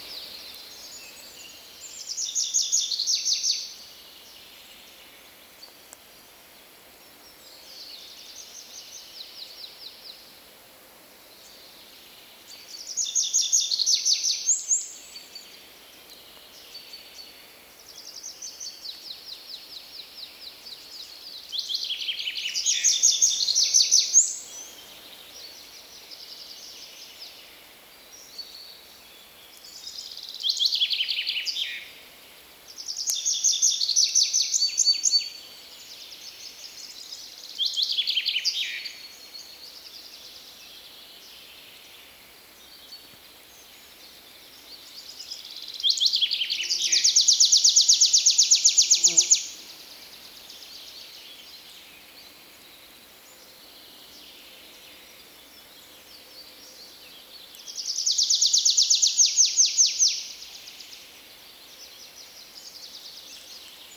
Птицы -> Коньки ->
лесной конек, Anthus trivialis